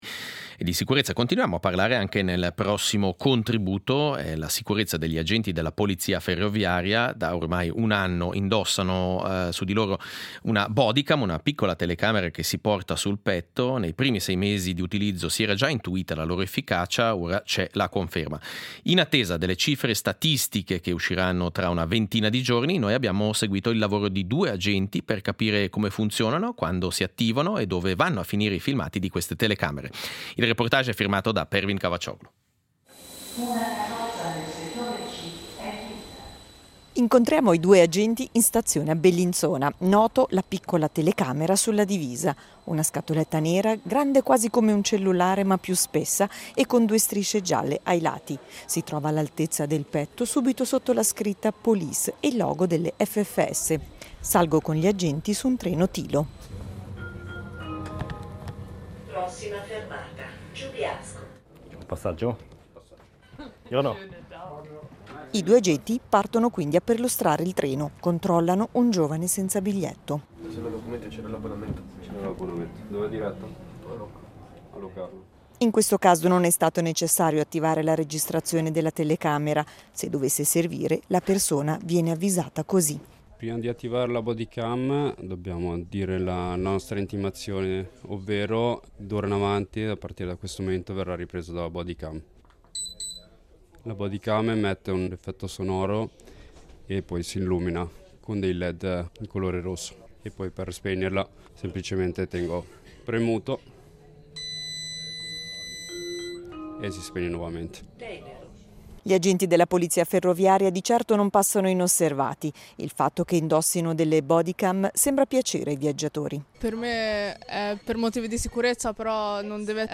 SEIDISERA del 17.10.2025: Reportage e intervista sulle bodycam degli agenti della polizia ferroviaria